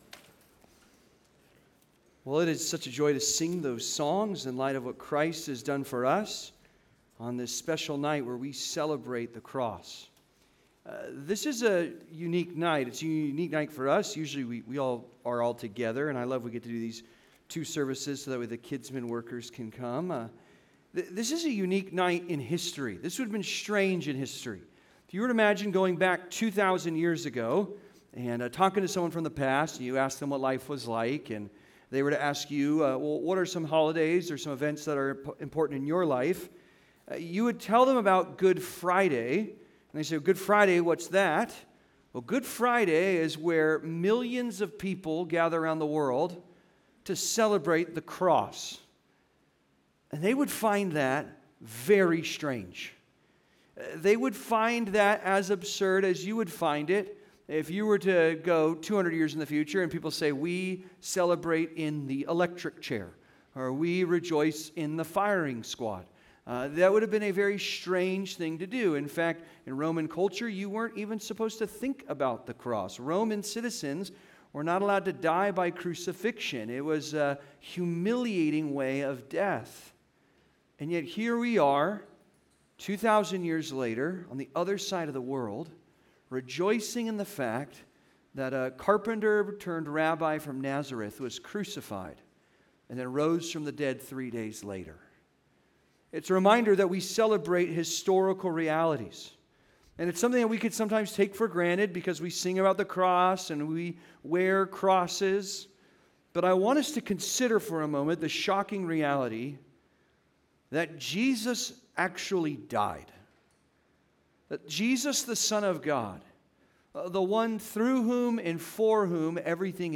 Saved in the Nick of Time: Good Friday (Sermon) - Compass Bible Church Long Beach